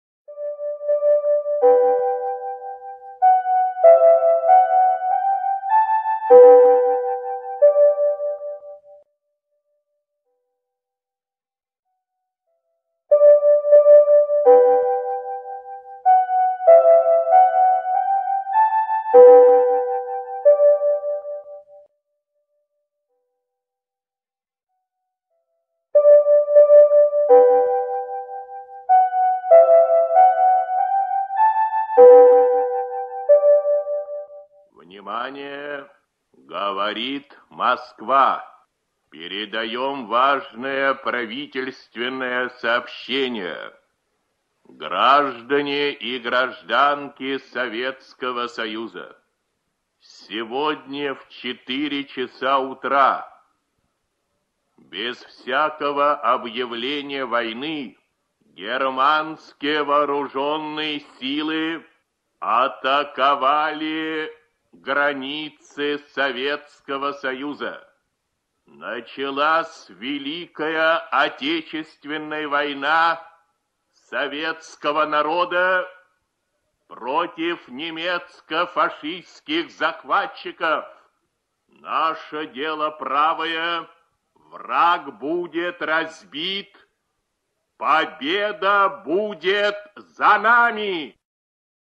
Правительственное сообщение о начале Великой Отечественной войны 22 июня 1941 г. Читает диктор Ю.Б. Левитан.
Levitan_Obyavlenie_o_nach_voyny.ogg